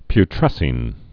(py-trĕsēn)